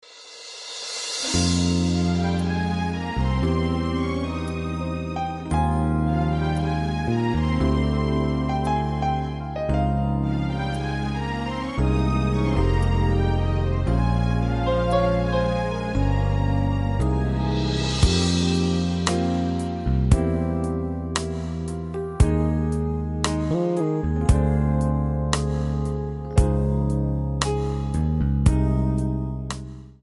MPEG 1 Layer 3 (Stereo)
Backing track Karaoke
Pop, Duets, 1990s